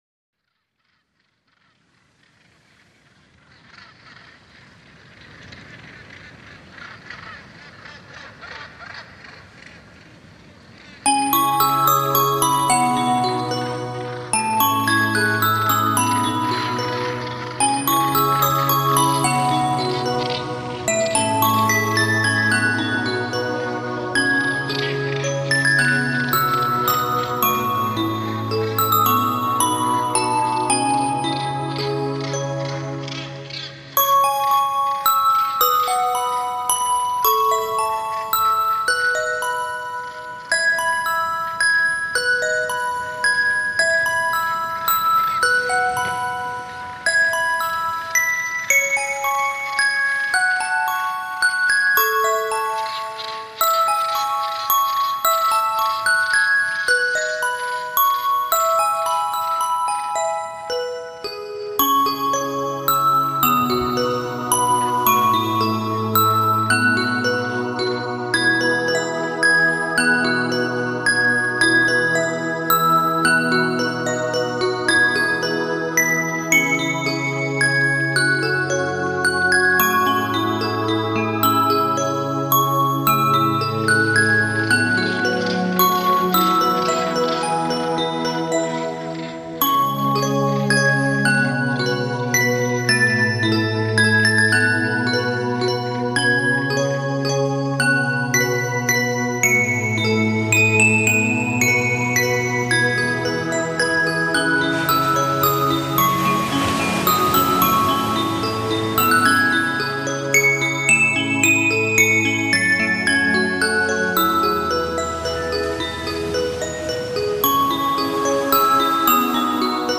音乐类别：新世纪音乐 > 轻音乐/器乐
沁凉透澈的琉璃，悠然写意的竖笛，浑然忘我的吉他，拍岸激扬的浪花，叫声不绝的海鸟，共谱一曲曲涤尘忘俗的大自然音乐。